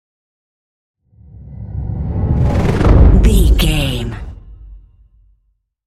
Chopper whoosh to hit large trailer
Sound Effects
Atonal
dark
intense
woosh to hit